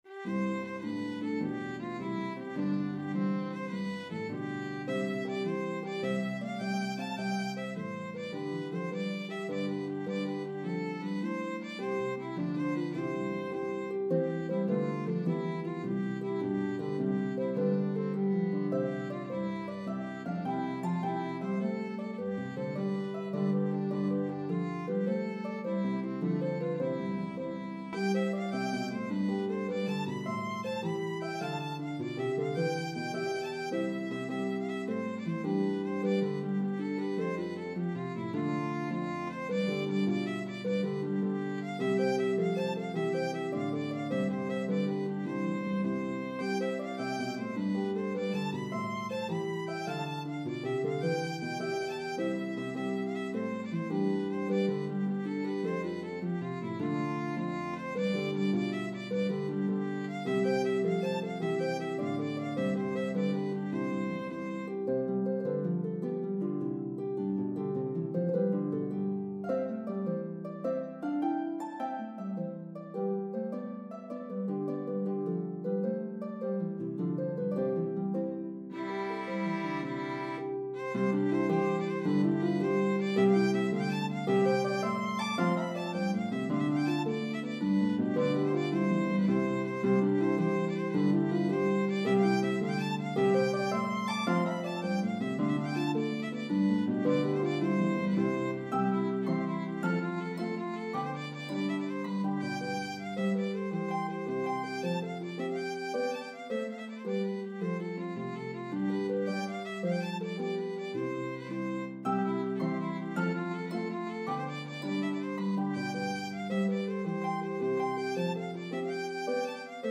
The medley progresses through 3 keys.